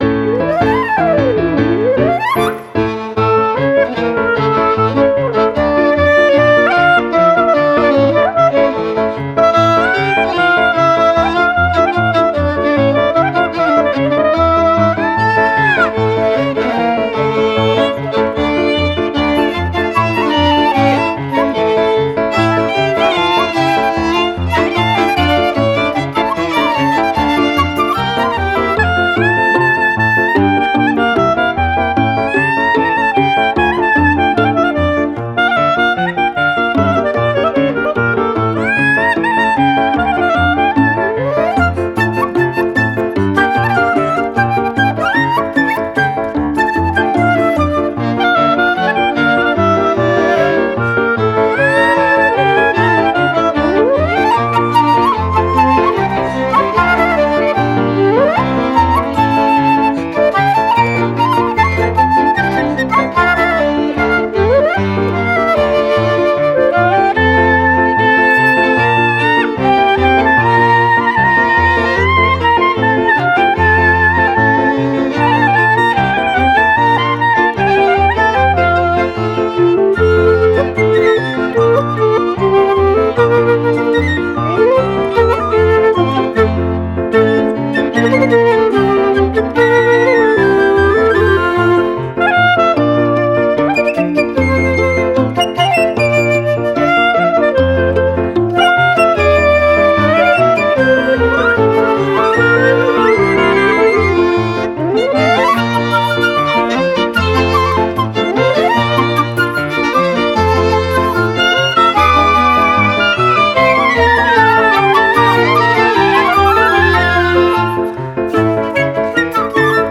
מנוגנים בכינורות, חלילים, קלרינטים, נבל ועוד כלים נוספים.